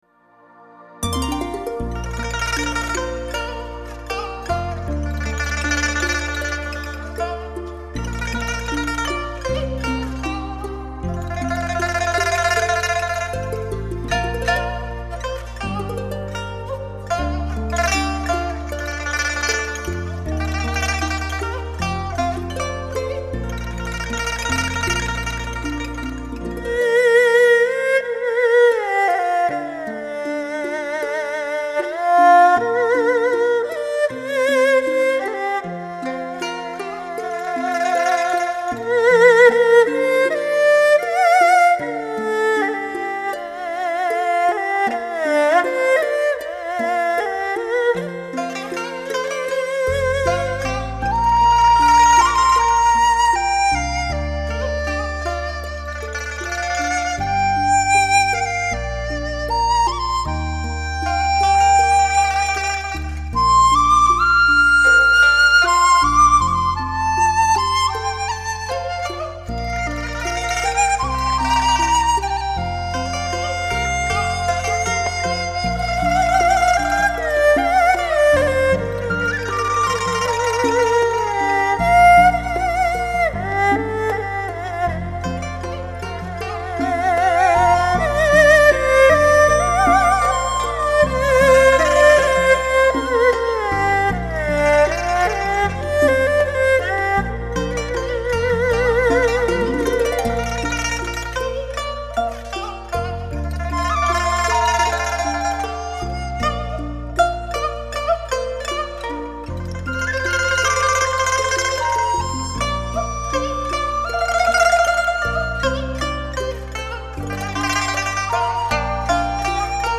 演奏乐器:二胡